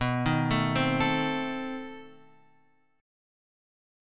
B 7th Flat 9th